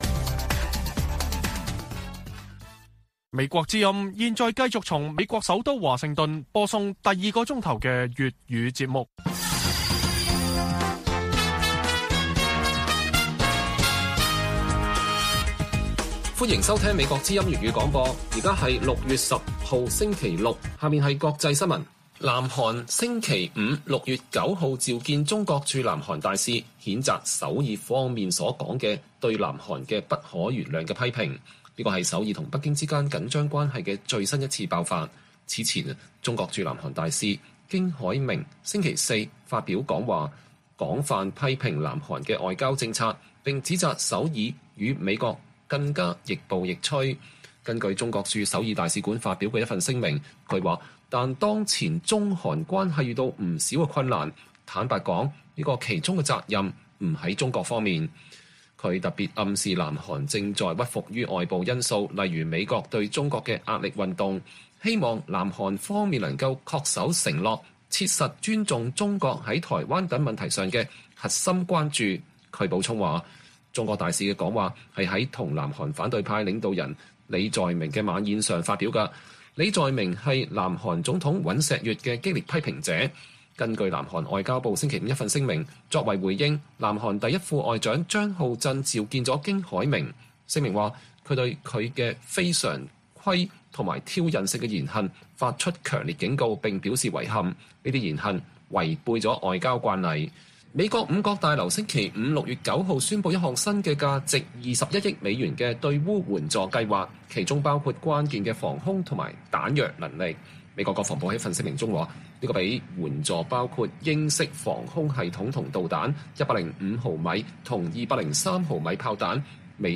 粵語新聞 晚上10-11點 : 美日台擬共享實時數據 美國據報對台交付先進無人偵察機